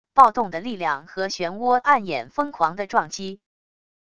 暴动的力量和漩涡暗眼疯狂的撞击wav音频